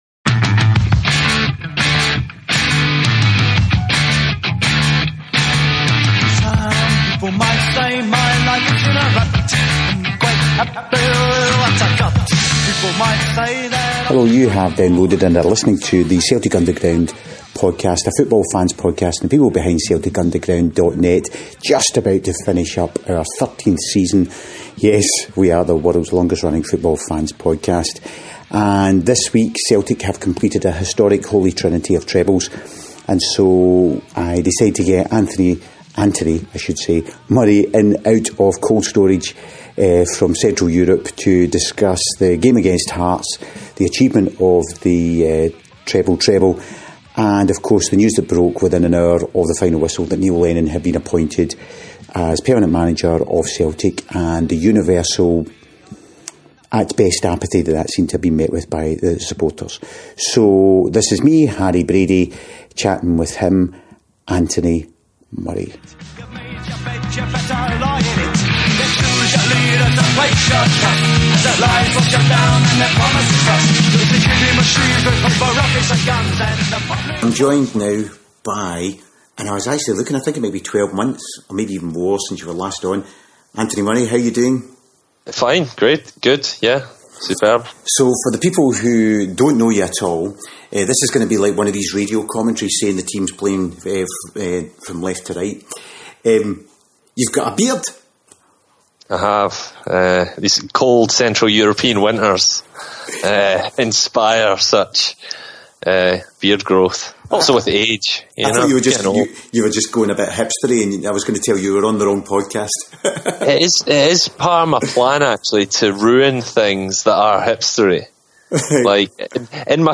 The podcast commenced with an idea that some like minded Celtic fans would record the type of chat we were having socially, like it and listen.